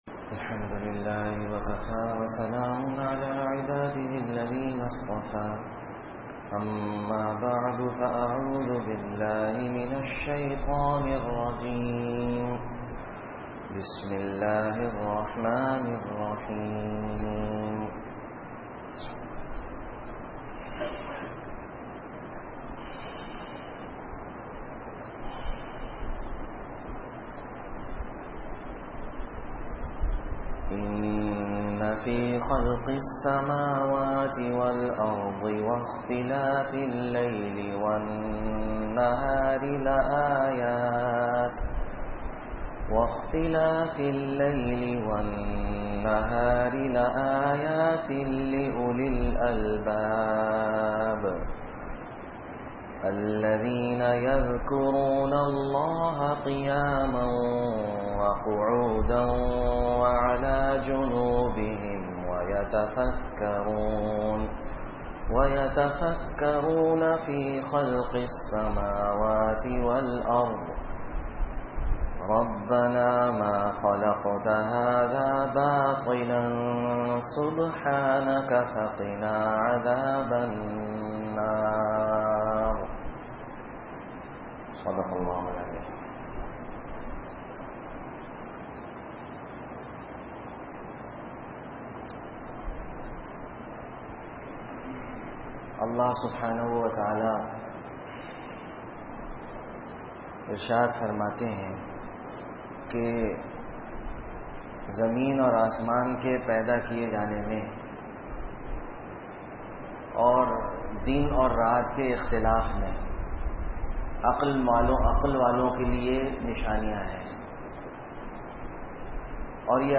12-Sept-2014 Friday Bayan